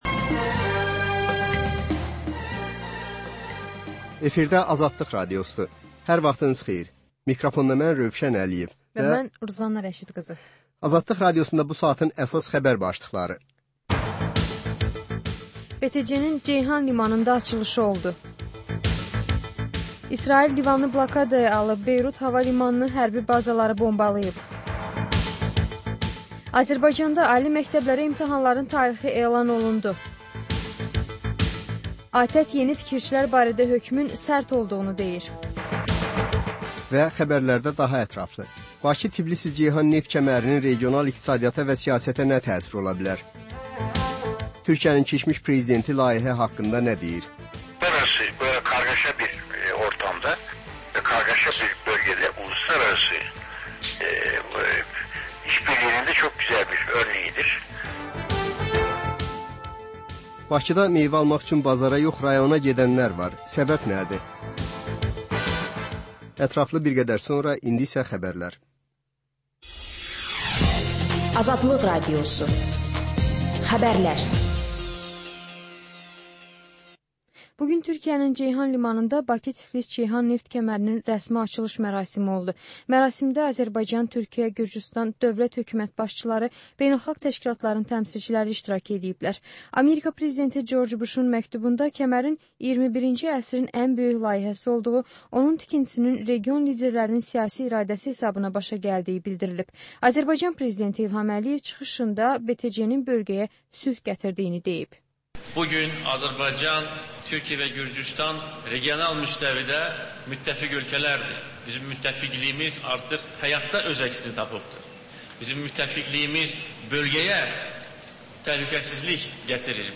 Bugün nələr gözlənir, nələr baş verib? Xəbər, reportaj, müsahibə.